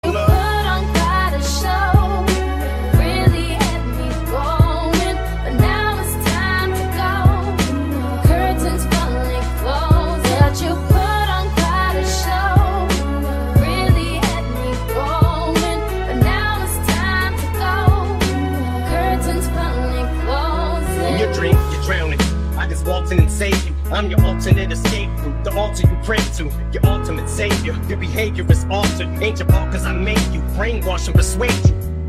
• Качество: 128, Stereo
mash up